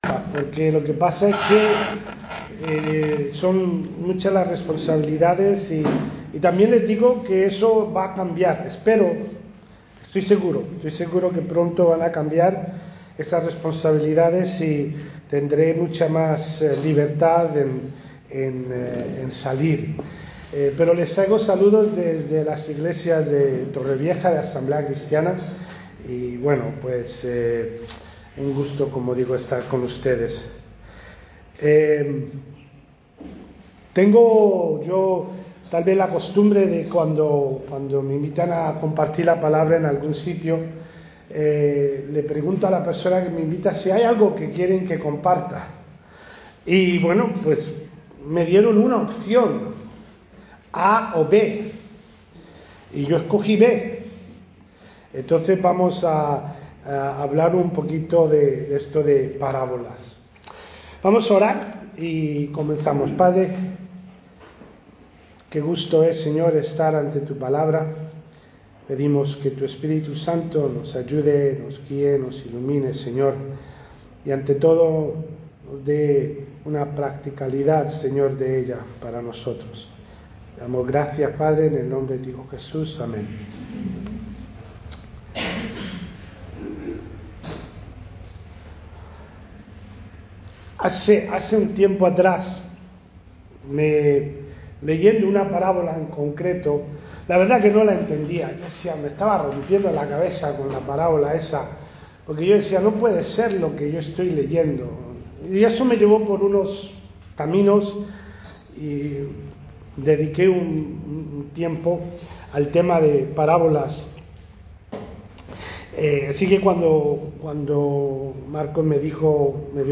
Service Type: Culto Dominical